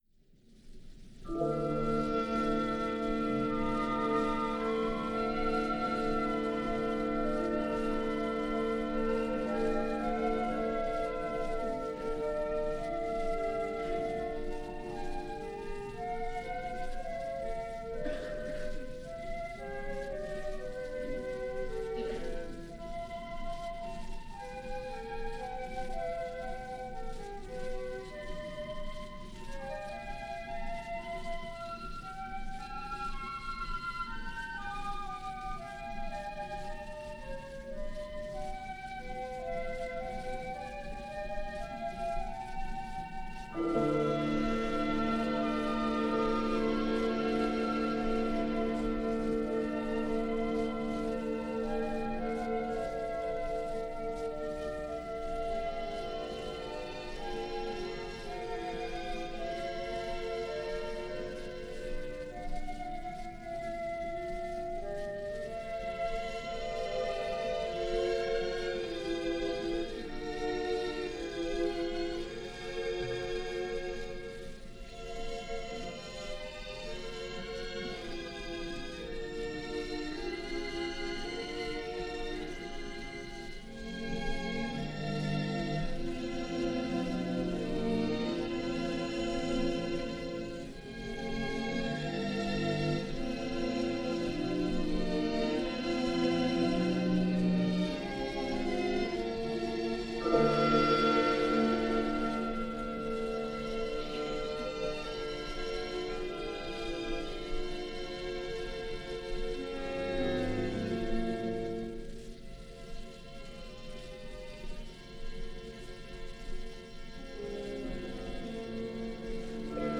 in a broadcast concert performance from 1952.
symphonie concertante for soli, choir and orchestra
Soprano
1952 Concert performance.